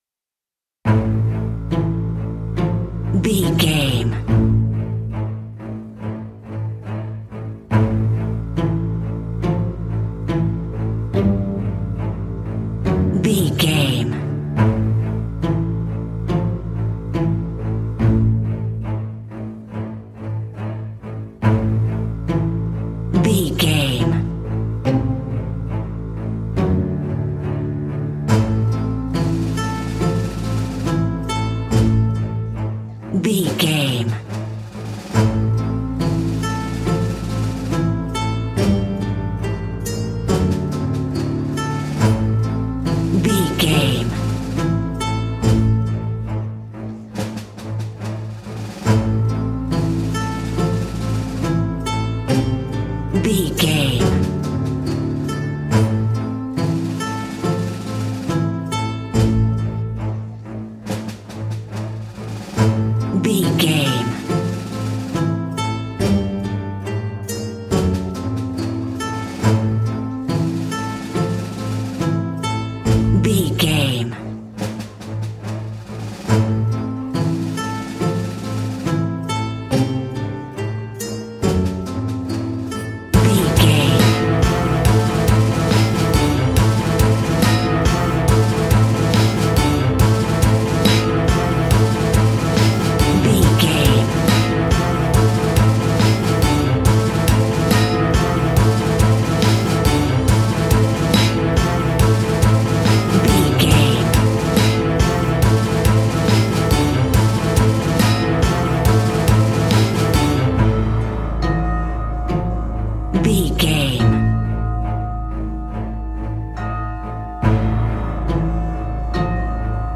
Aeolian/Minor
ominous
eerie
strings
acoustic guitar
harp
drums
percussion
horror music